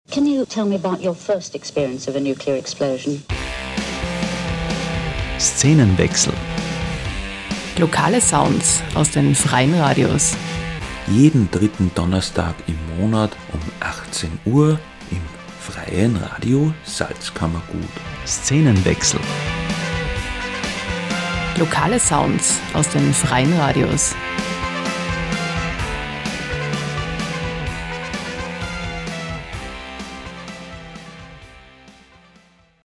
Sendungstrailer
FRS-TRAILER-SZENENWECHSEL-3-DONNERSTAG-18-UHR.mp3